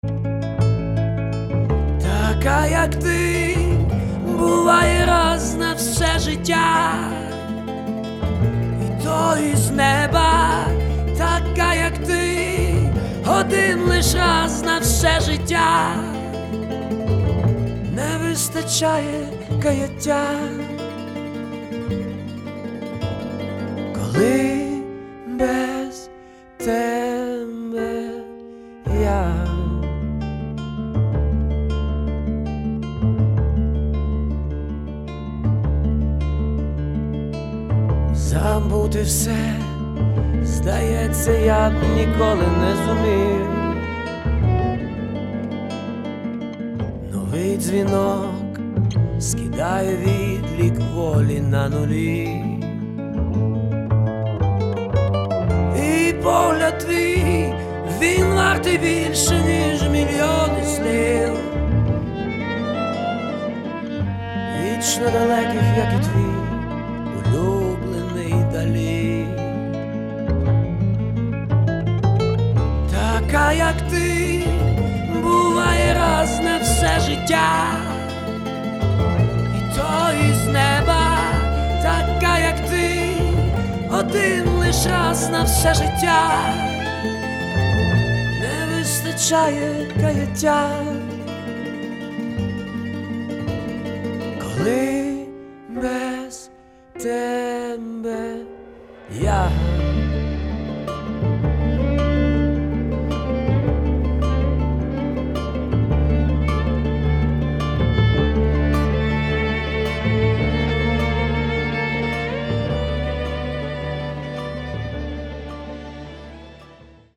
• Качество: 320, Stereo
красивые
спокойные
скрипка
нежные